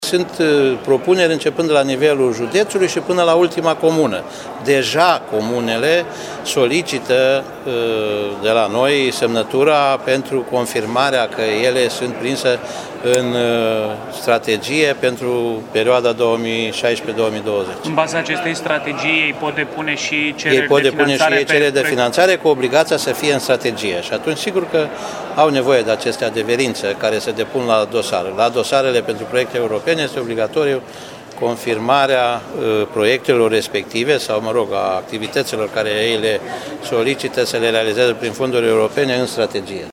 Consiliul Județean Timiș a aprobat astăzi strategia de dezvoltare a județului pentru perioada 2016 – 2020. Documentul, care numără 400 de pagini, este foarte important pentru că în baza lui, județul și comunele din Timiș pot depune cererile de finanțare europeană, spune liderul CJT, Titu Bojin: